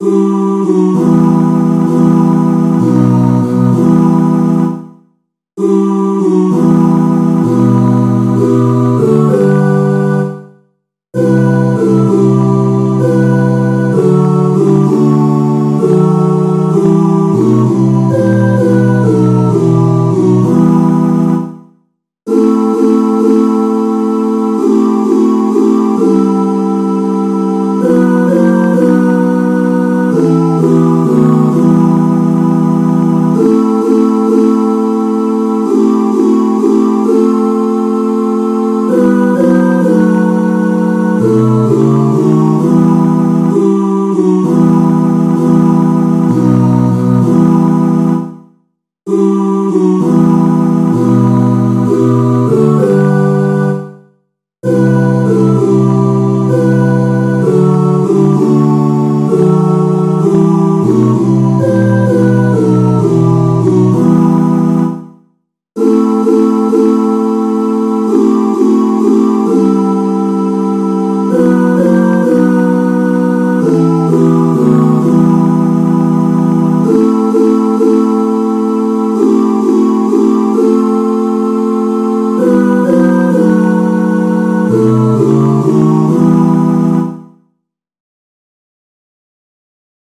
Jewish Folk Song for Pesach (Passover)
Choral Arrangement
G minor ♩= 65 bpm
niggun_025a_eliyahu_choral_01.mp3